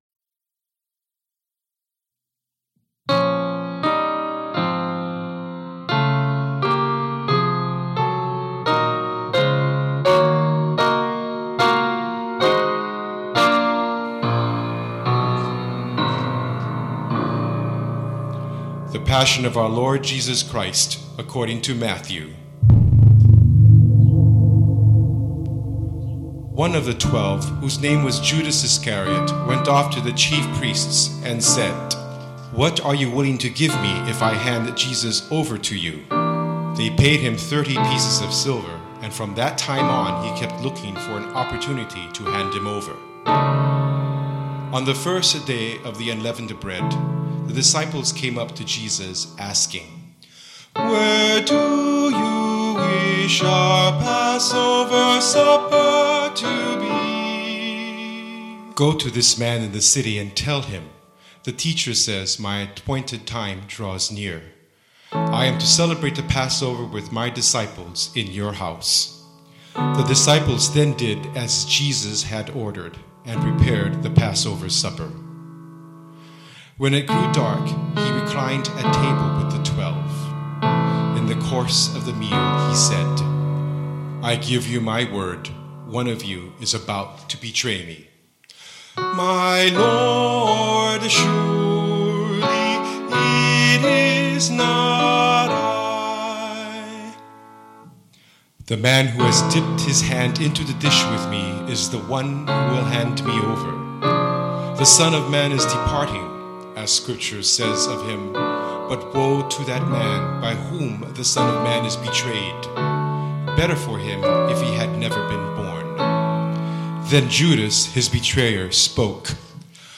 following mp3's are "practice" recordings